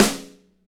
SNR MTWN 09R.wav